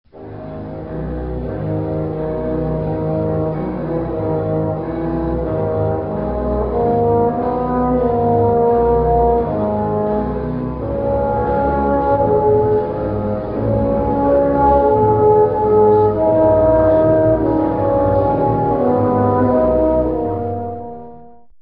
Le Tuba
Quelques oeuvres pour découvrir l'instrument : "Byello" extrait des tableaux d'une exposition de Modeste MOUSSORGSKY Description: Le tuba est un instrument à vent et à piston qui sert de basse à la famille des cuivres.
Les tubas en usage de nos jours comportent de trois à six pistons et produisent un son semblable, en plus fort, à celui du cor.
tuba_orchestre.mp3